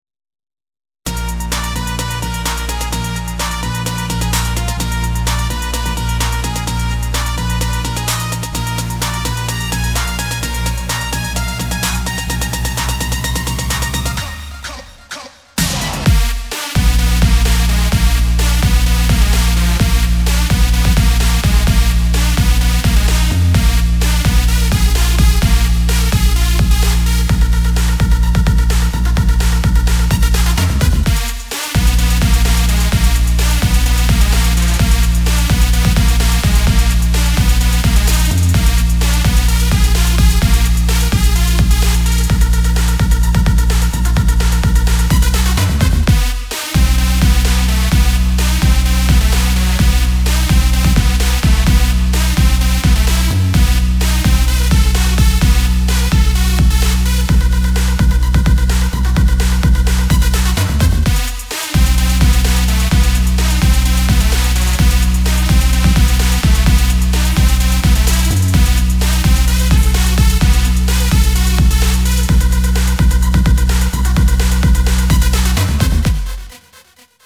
זה בעיה בהקלטה לפעמים חסר קיק וכל מיני דברים כי זה ההקלטה של האורגן וכידוע זה זבל